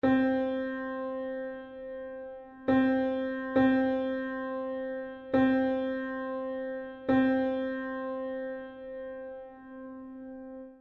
With regard to notes of larger value, in slow movements, it is clear that the spirit rather than the letter of our law is to be regarded.
it would obviously be absurd to shorten the first dotted note by one-half; this rendering seems to me the proper one: